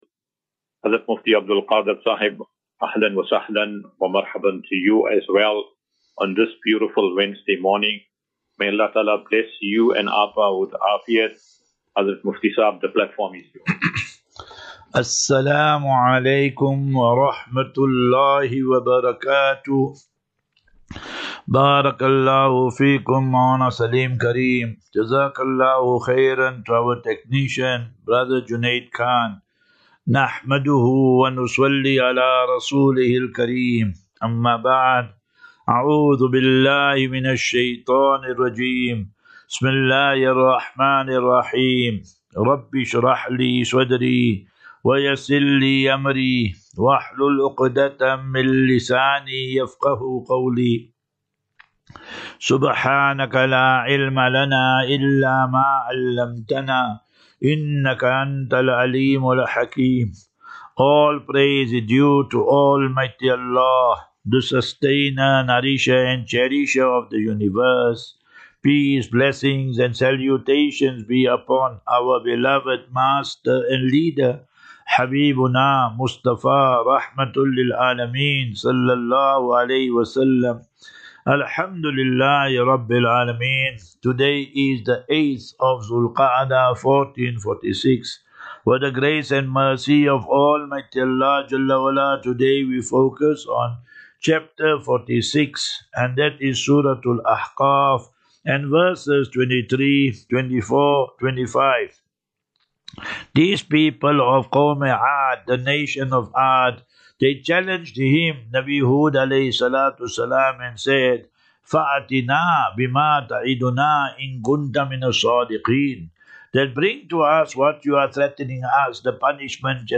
Assafinatu - Illal - Jannah. QnA